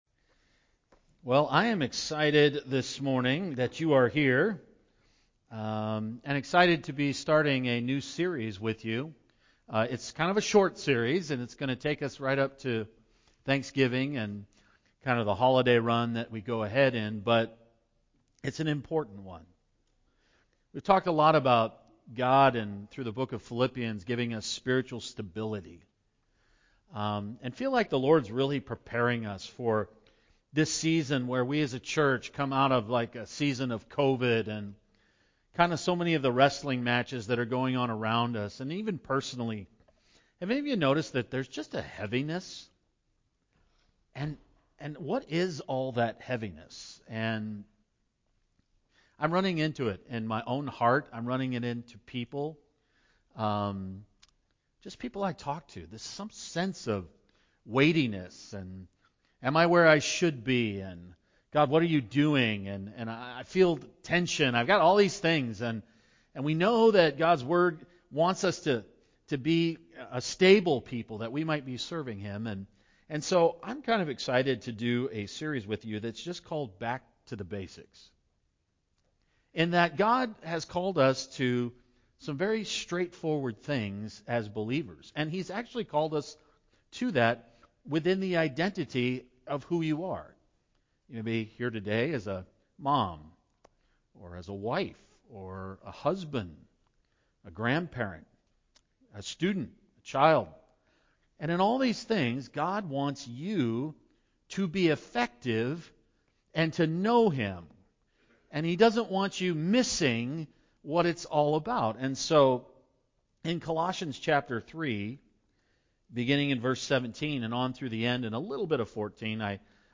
Passage: Colossians 3:17-25 Service Type: Sunday morning